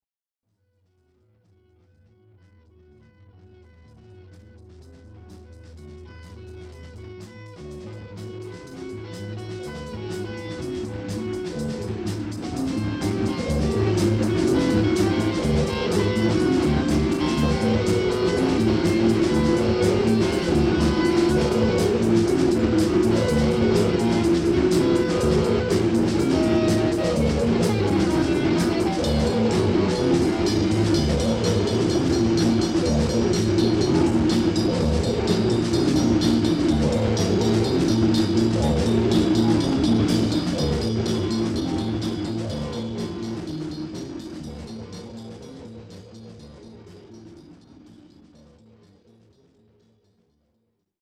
e-bass
guitar
voc, perc.
perc., sax, flute
tuba, microsynth
drums, perc.